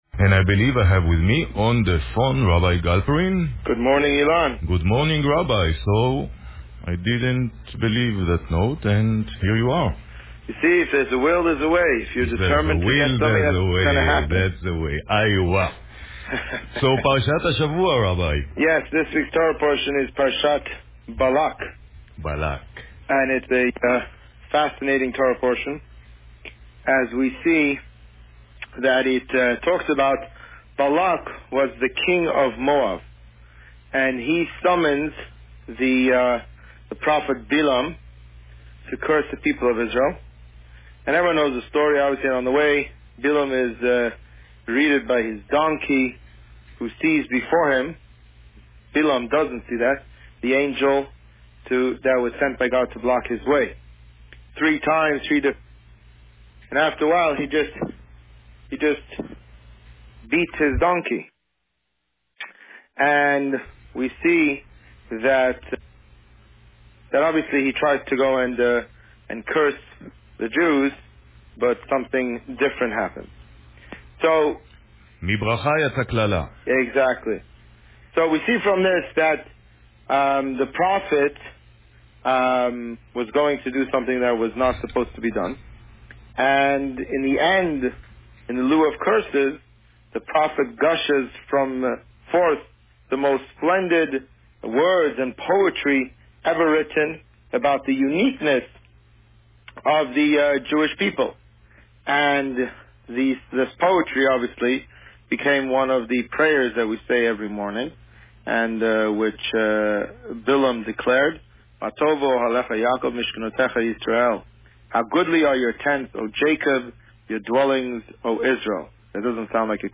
This week, the Rabbi spoke about Parsha Balak. Listen to the interview here.